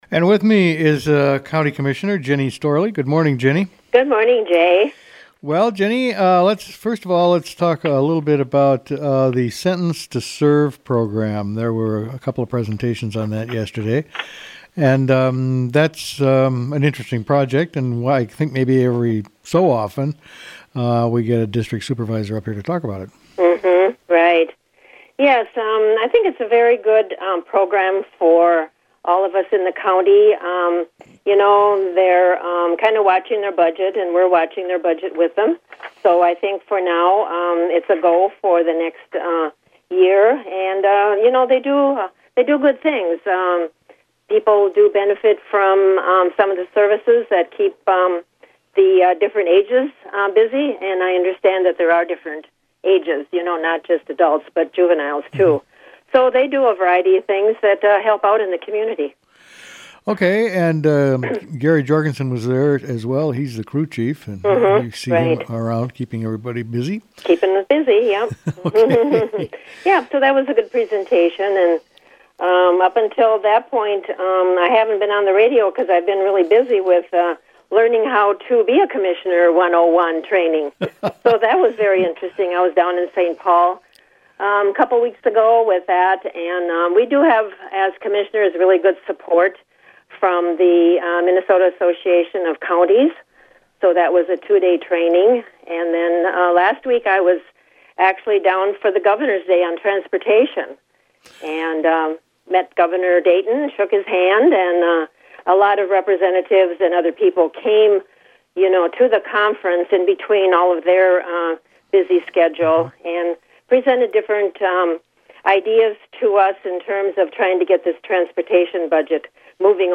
5th District Commissioner Ginny Storlie.